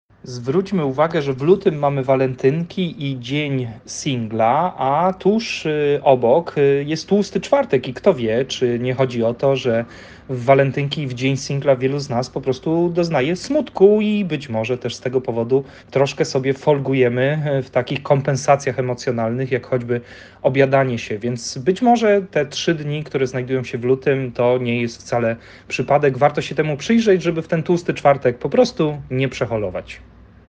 Na te pytania odpowiada trener mentalny